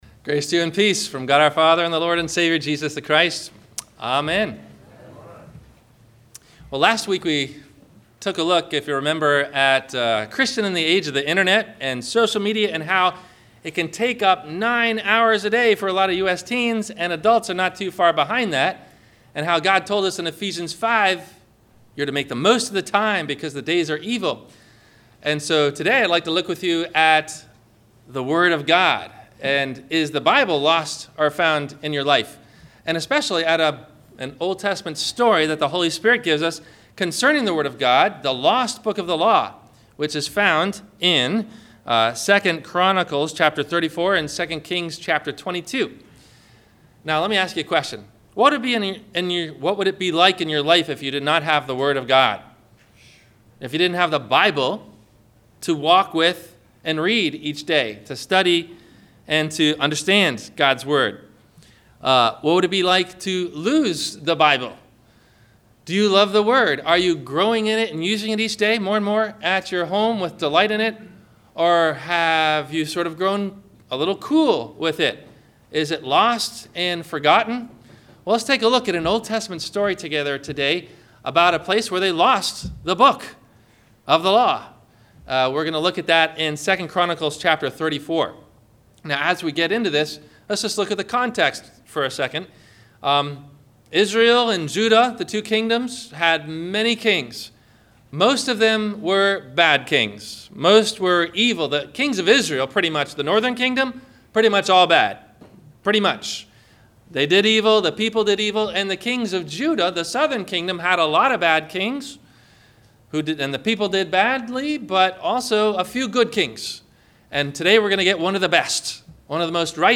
Is Your Bible Lost or Found? - Sermon - August 21 2016 - Christ Lutheran Cape Canaveral